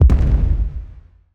Explosion and footstep SFX
EXPLDsgn_Explosion Impact_14.wav